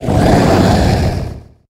Cri d'Hastacuda dans Pokémon HOME.